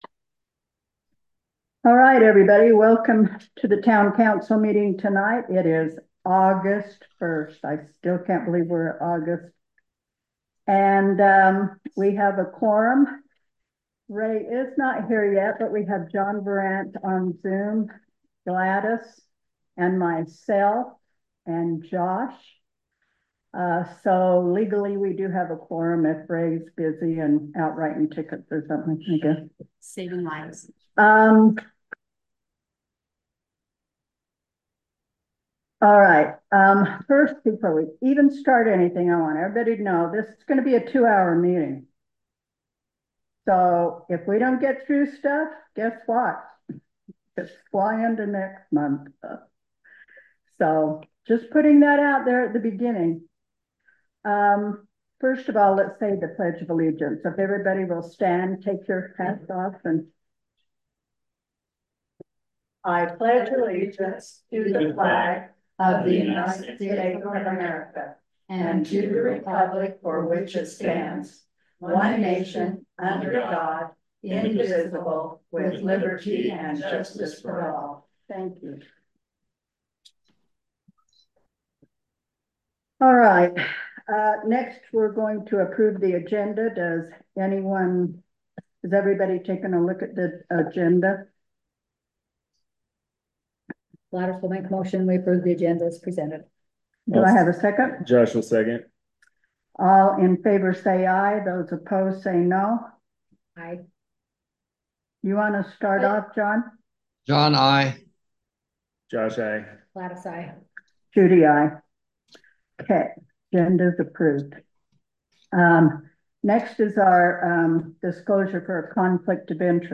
Town Council, Regular Meeting, Thursday, August 1, 2024 7:00PM | Boulder, Utah
The Boulder Town Council will hold its regular meeting on Thursday August 1, 2024, starting at 7:00 pm at the Boulder Community Center Meeting Room, 351 No 100 East, Boulder, UT. Zoom connection will also be available.